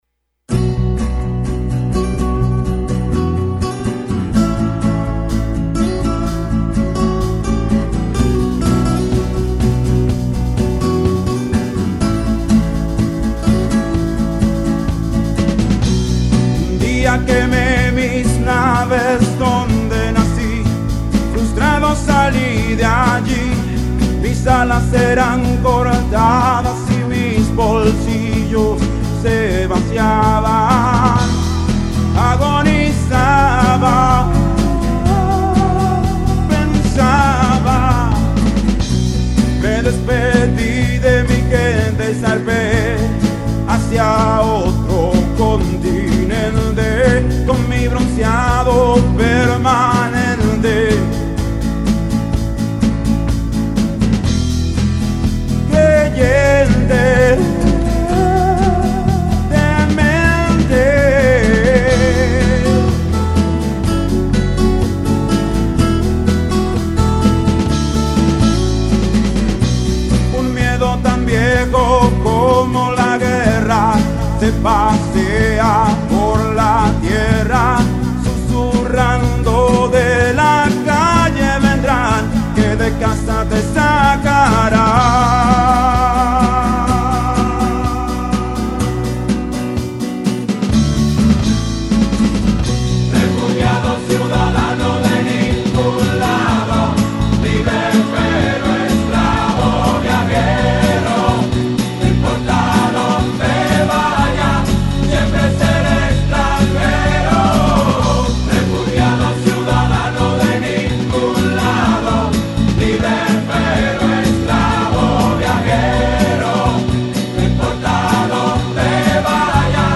Intentando tocar la batería de